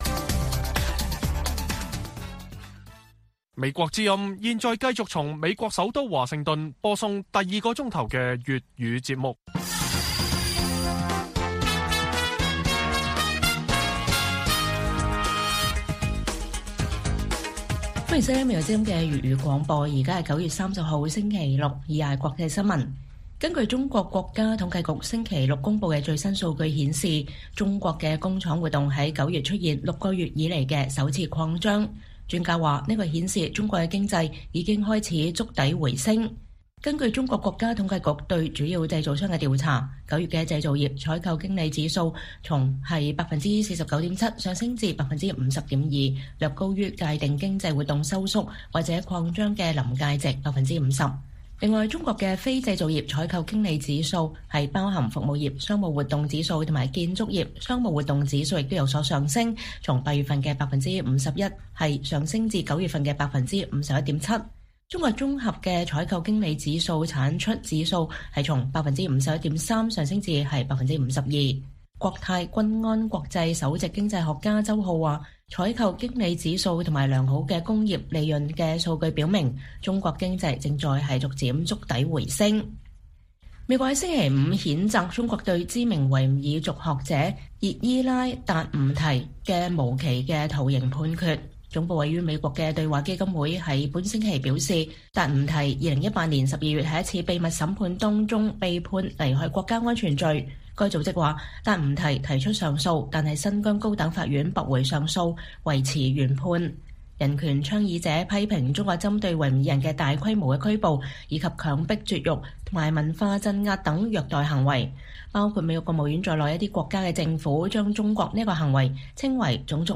粵語新聞 晚上10-11點: 中國房市低迷下 官方公佈新數據顯示經濟有觸底回升跡象